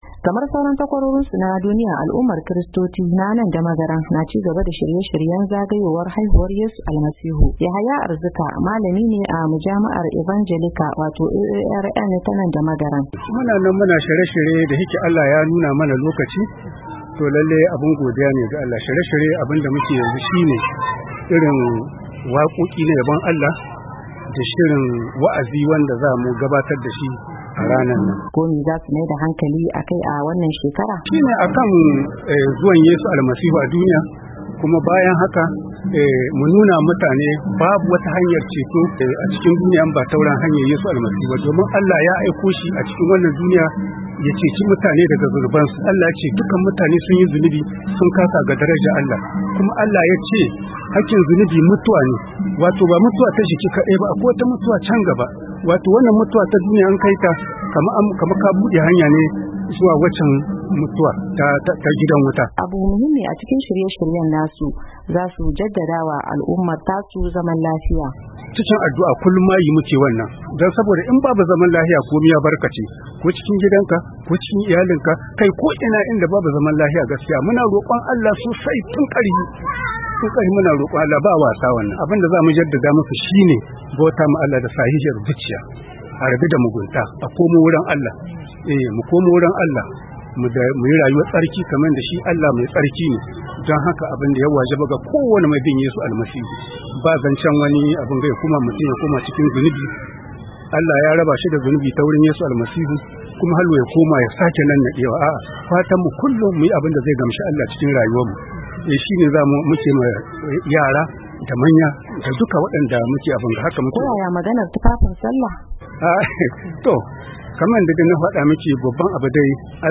Ga cikakken rahoton wakiliyarmu daga Nijar.